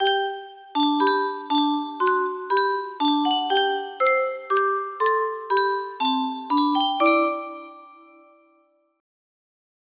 Clone of Yunsheng Spieluhr - Das Deutschlandlied 2247 music box melody
Yay! It looks like this melody can be played offline on a 30 note paper strip music box!